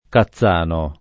kaZZ#no] top.